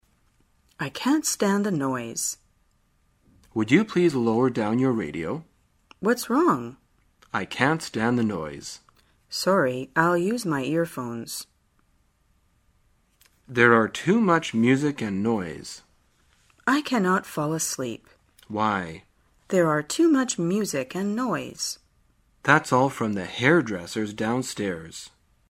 在线英语听力室生活口语天天说 第12期:怎样抱怨噪音的听力文件下载,《生活口语天天说》栏目将日常生活中最常用到的口语句型进行收集和重点讲解。真人发音配字幕帮助英语爱好者们练习听力并进行口语跟读。